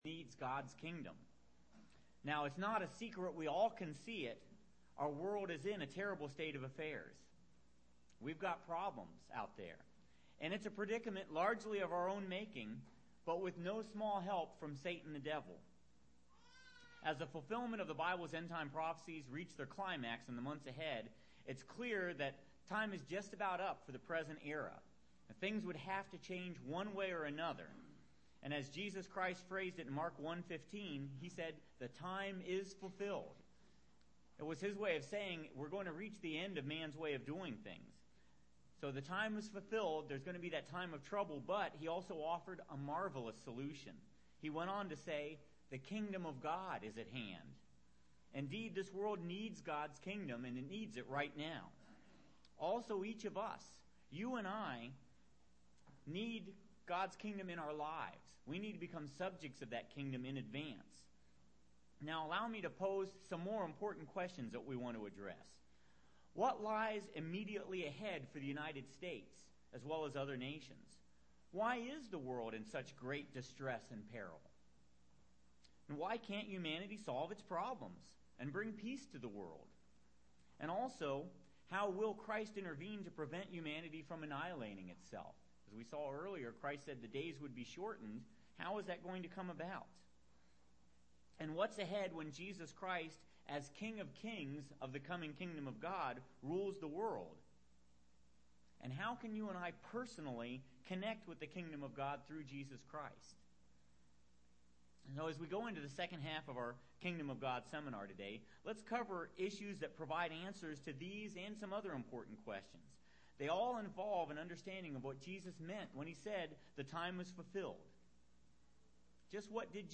Learn more in this Kingdom of God seminar.